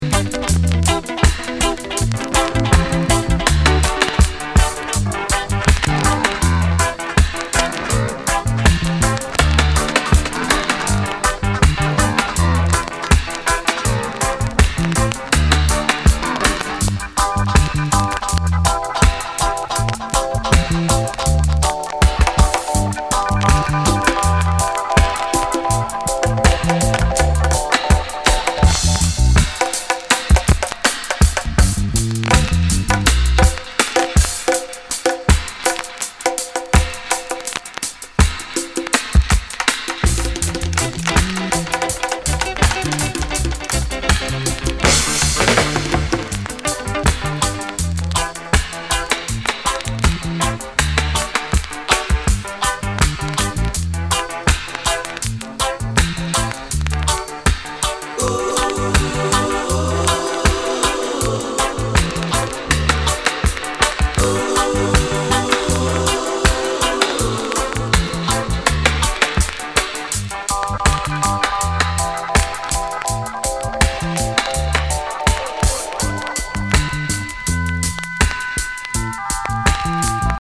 Genre:  Soul/Reggae